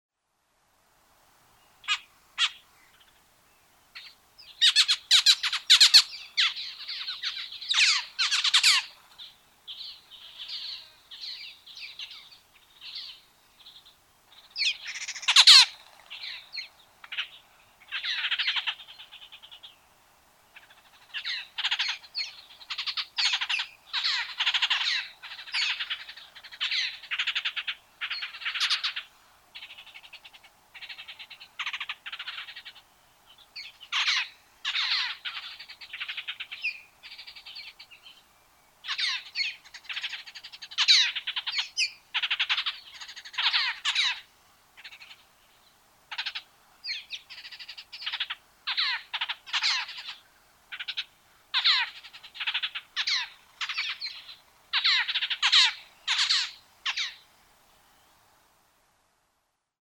Tordo – Aprende con Ciencia
Curaeus-curaeus-Tordo.mp3